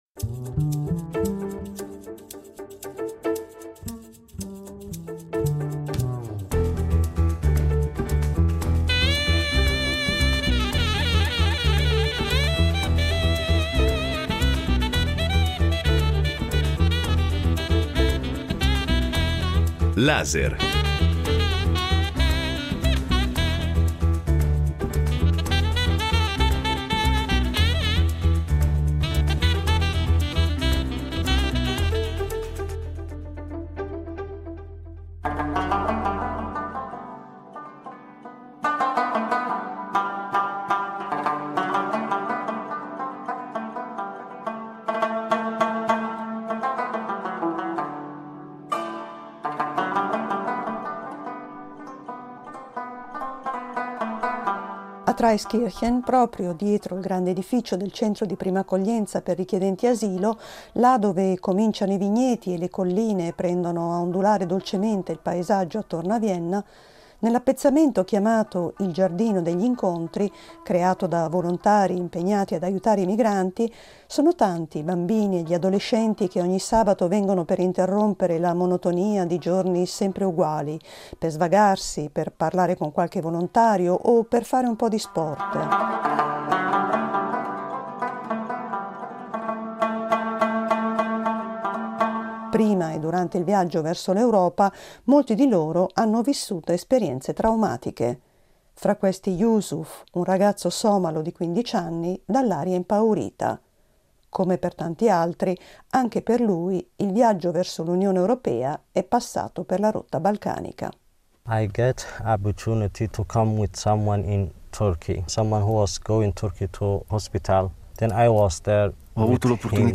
Testimonianze dal centro di prima accoglienza di Traiskirchen, seconda parte